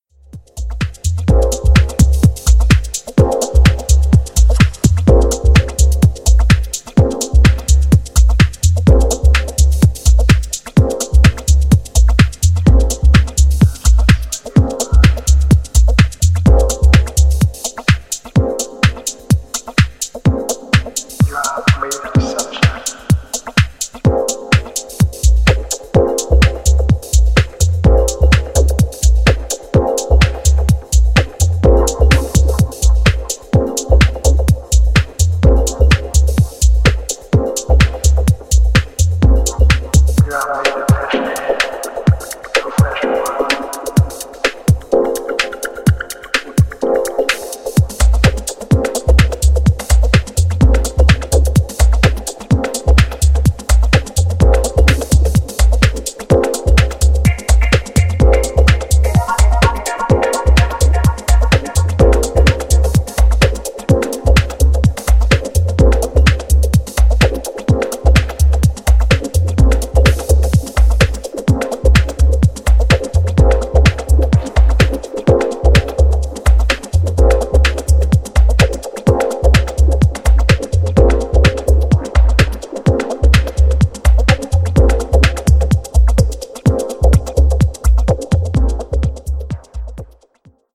一瞬差し込まれるブロークンなリズム、端正な鳴り。